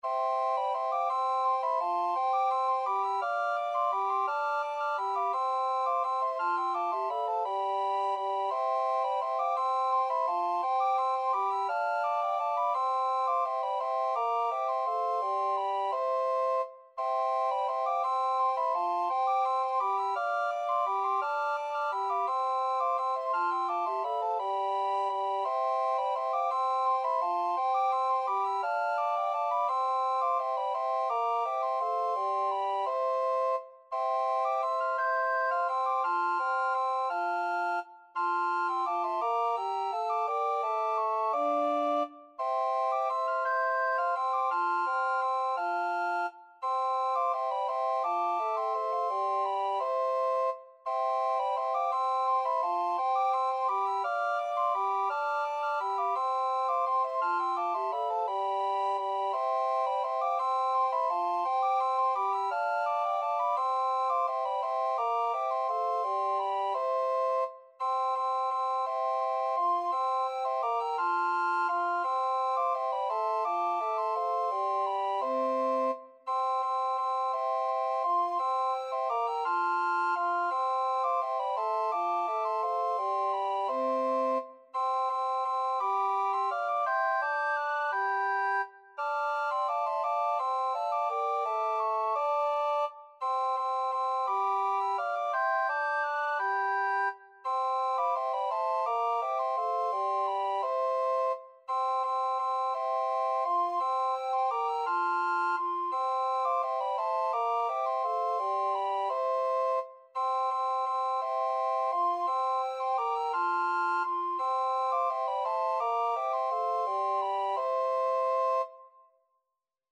Free Sheet music for Recorder Quartet
Soprano RecorderAlto RecorderTenor RecorderBass Recorder
3/2 (View more 3/2 Music)
C major (Sounding Pitch) (View more C major Music for Recorder Quartet )
Classical (View more Classical Recorder Quartet Music)
danserye_2_bergerette_RECQ.mp3